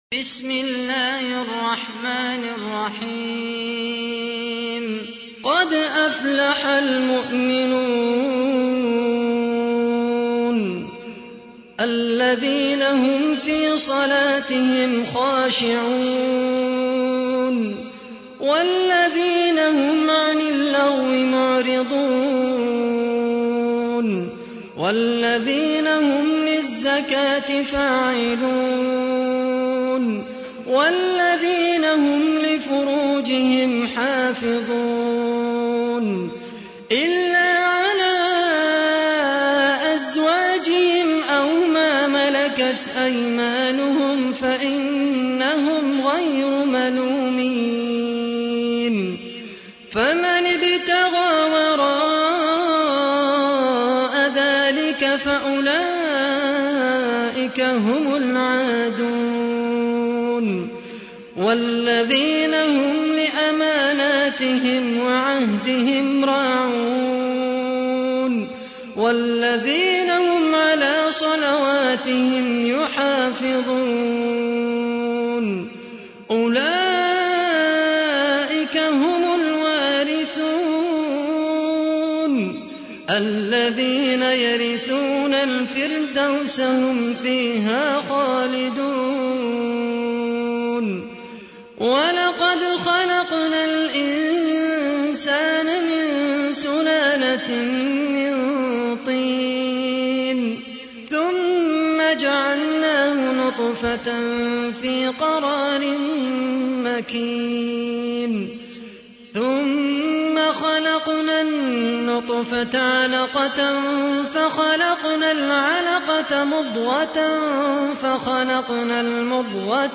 تحميل : 23. سورة المؤمنون / القارئ خالد القحطاني / القرآن الكريم / موقع يا حسين